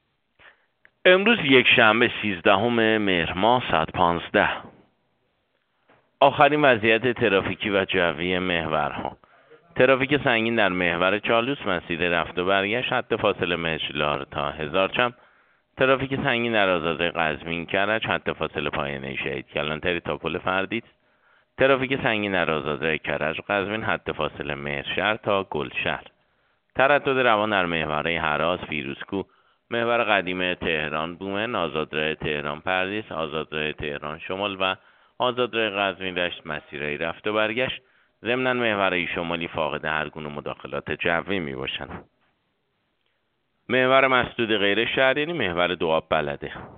گزارش رادیو اینترنتی از آخرین وضعیت ترافیکی جاده‌ها ساعت ۱۵ سیزدهم مهر؛